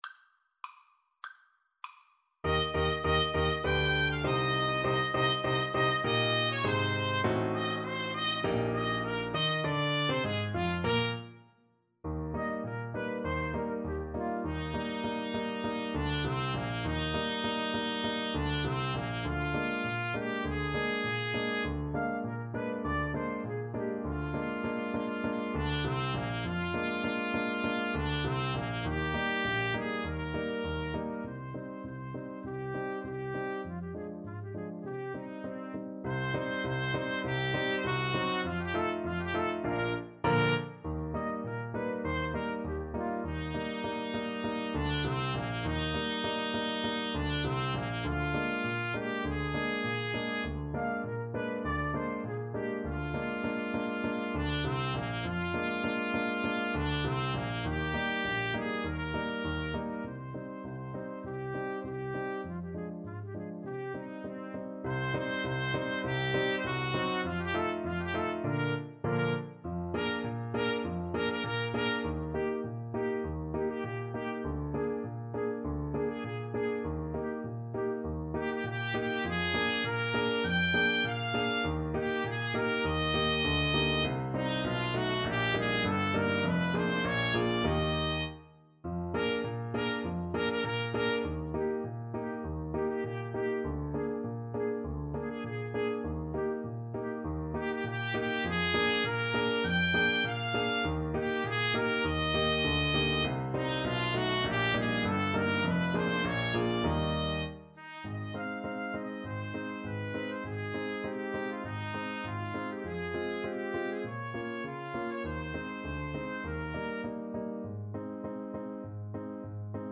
Marziale = c. 100